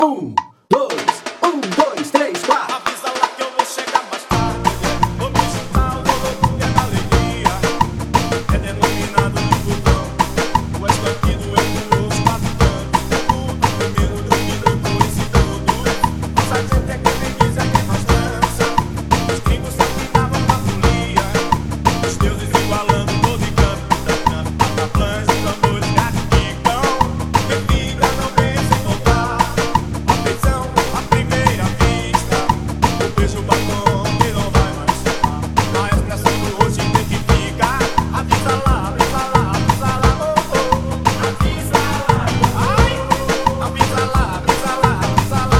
VS DE AXÉ